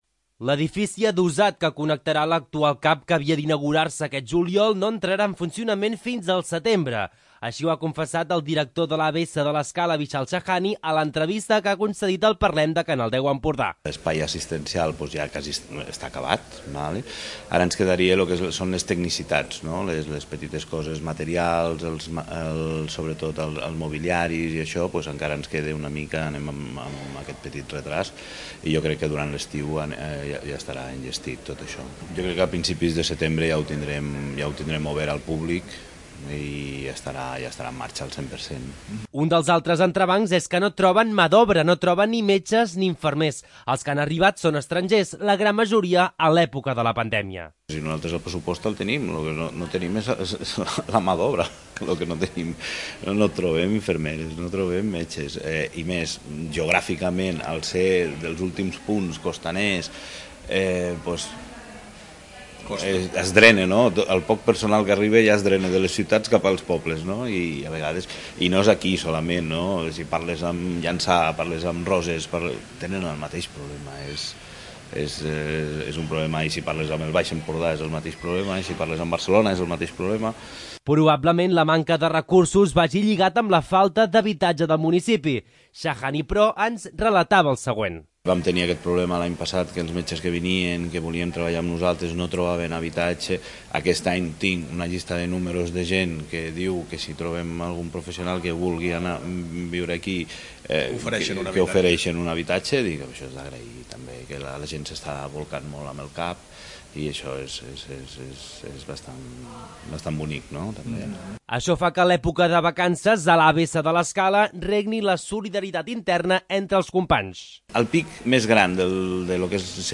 Aquests són petits extractes de l'entrevista que podeu veure sencera a la web de Canal10 Empordà.